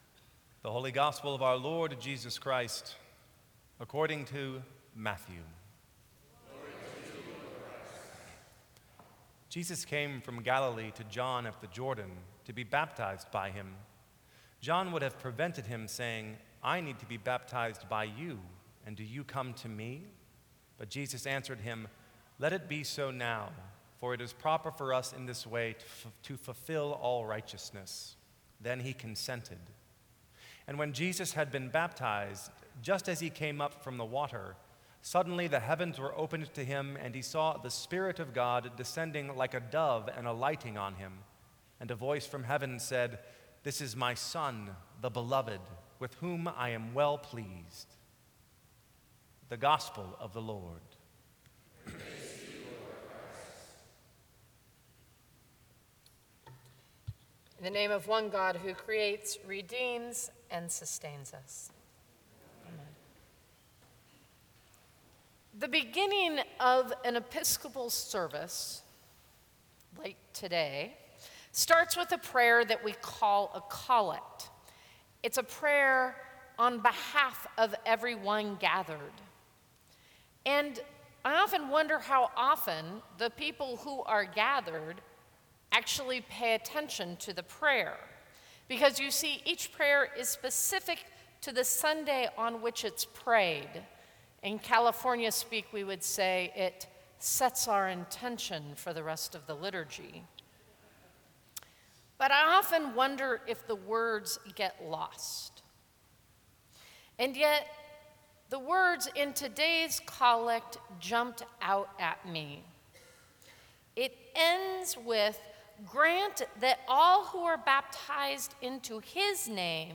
Sermons from St. Cross Episcopal Church Baptism: Boldly Confess.